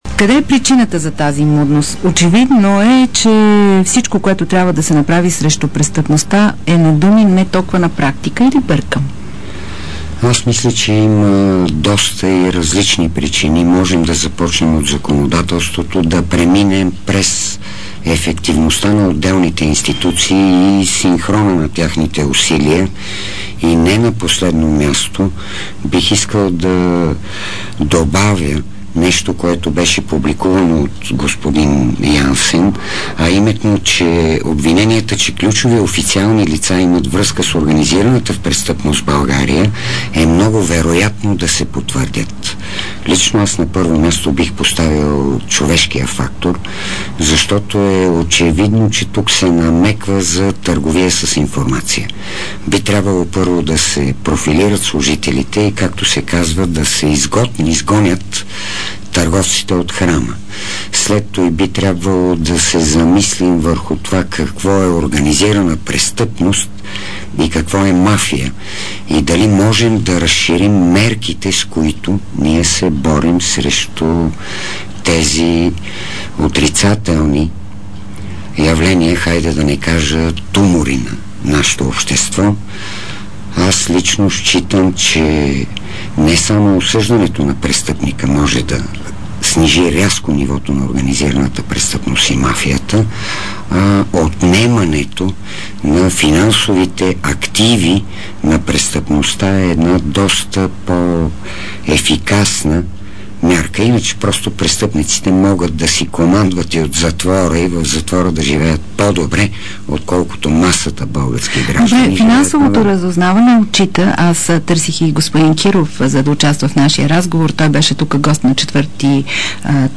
DarikNews audio: Интервю с бившия директор на финансовото разузнаване доц.